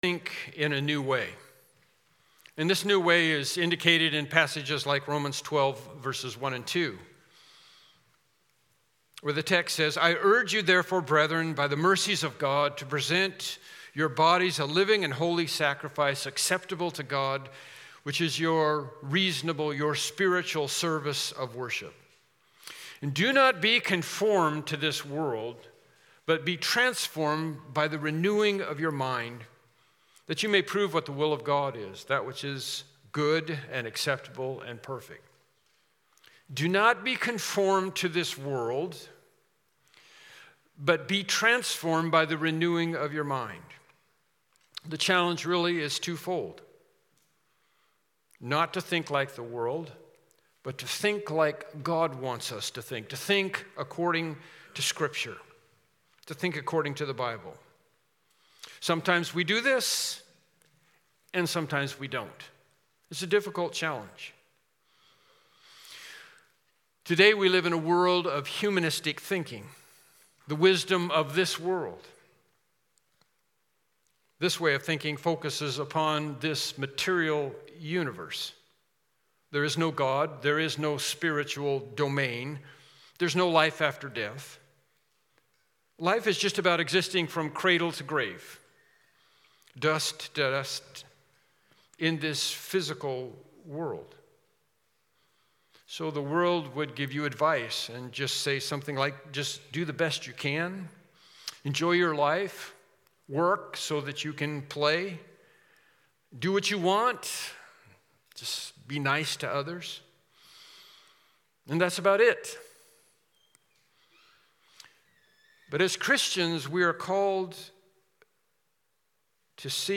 Mark 3:1-6 Service Type: Morning Worship Service « God Is and He Has Spoken